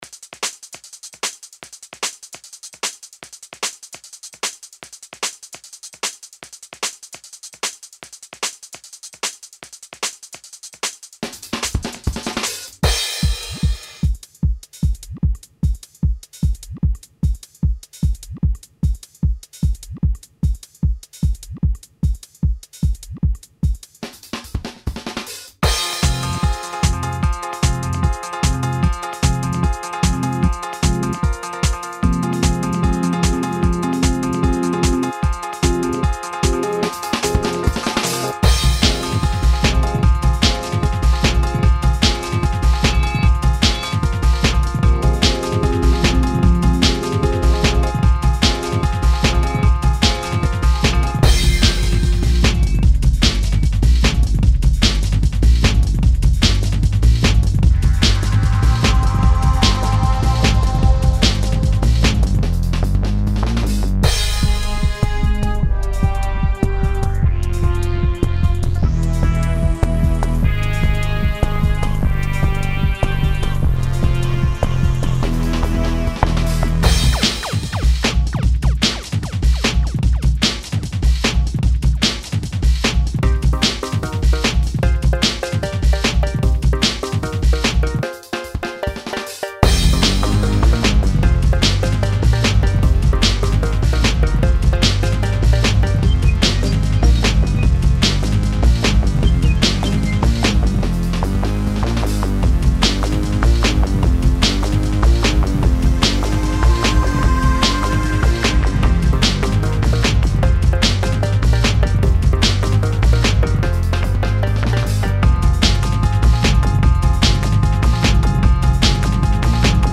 dance/electronic
Loads of cool classic acid sounds and riffs to sample.